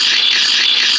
scrape.mp3